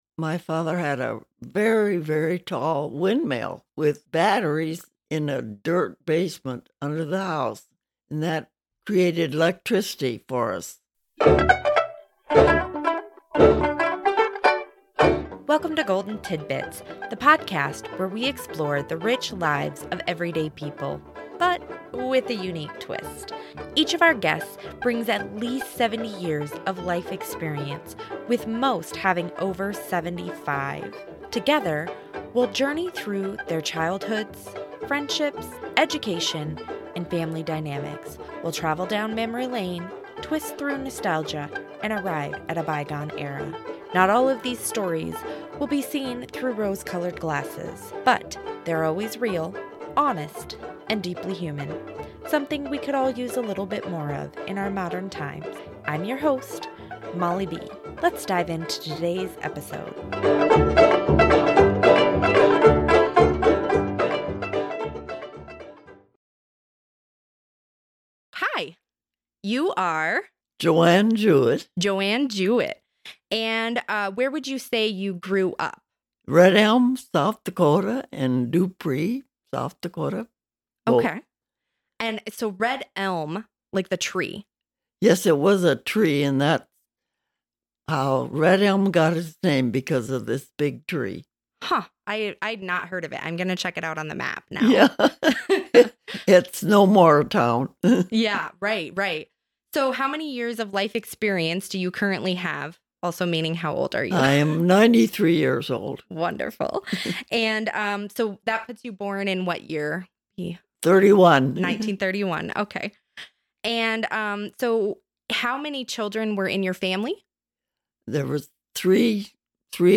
In each episode, we sit down with individuals aged 75 and older to uncover their life stories, wisdom, and the defining moments that have shaped their journeys.
Conversations